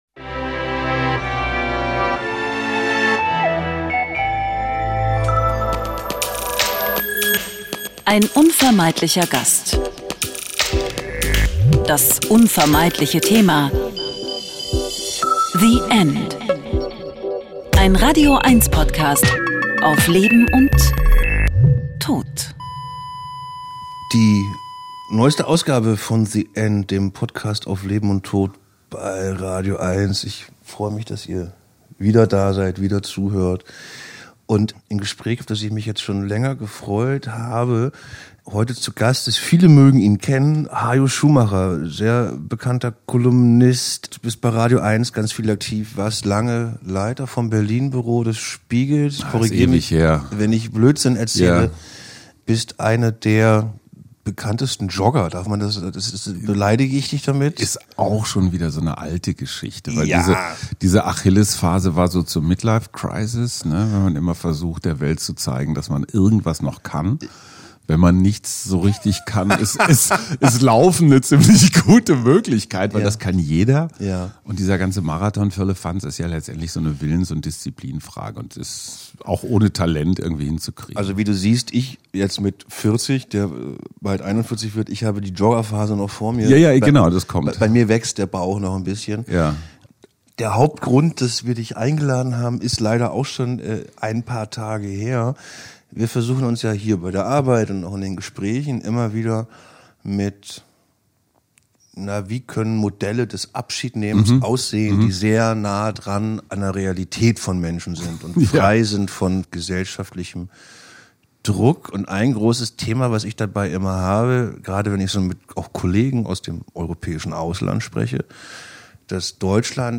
Ein Gespräch über Alterssozialismus, Einsamkeit und Zusammenleben im Alter und über die Sinn-Frage nach einem gelungenen Leben.